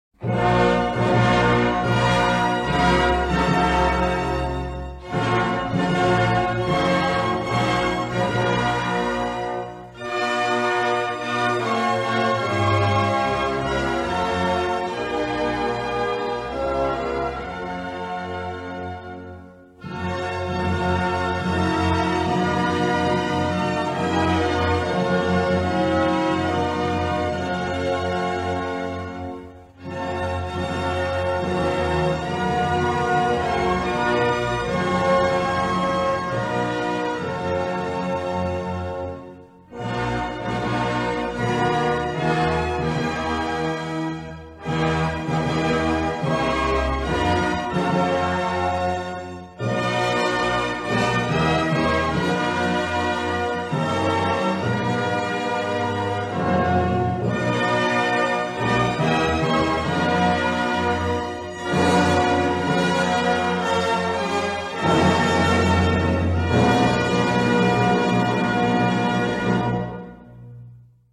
Swiss_Psalm_(official_instrumental).mp3